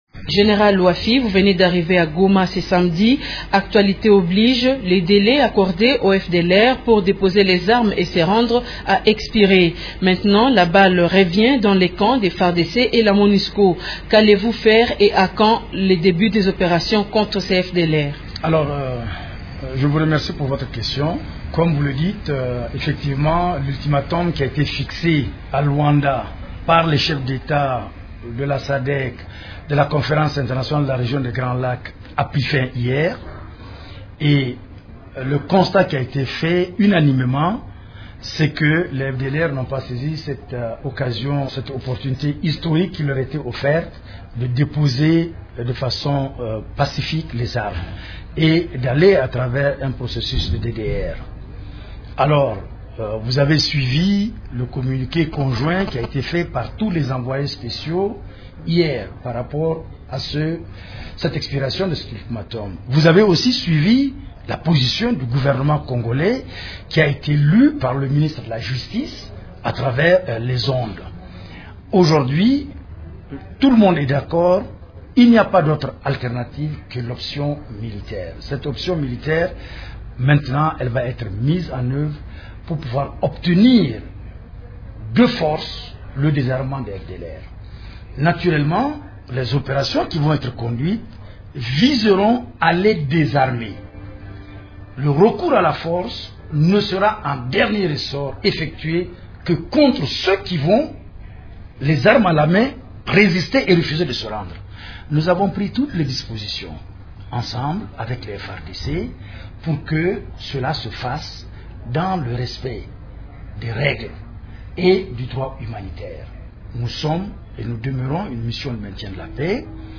Le représentant spécial adjoint du secrétaire général de l’ONU chargé de l’Est de la RDC, Abdallah Wafy, est l’invité de Radio Okapi ce lundi 5 janvier pour en parler.